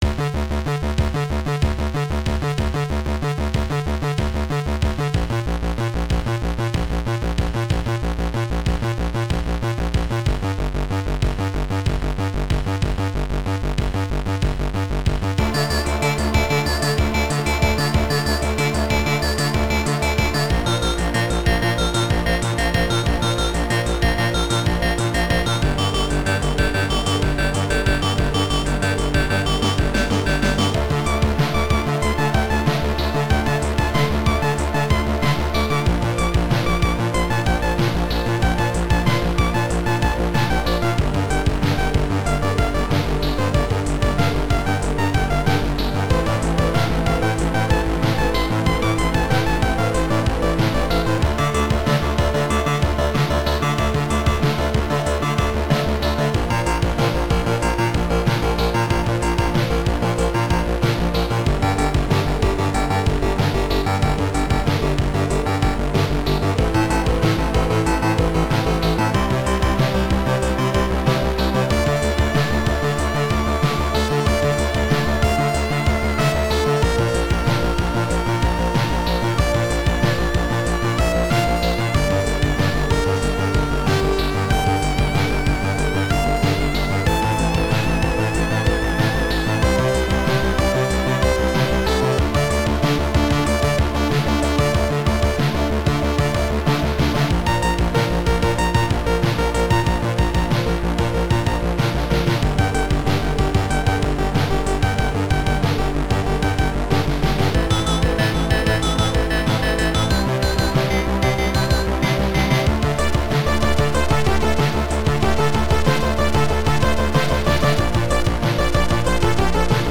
Synth
SoundMon module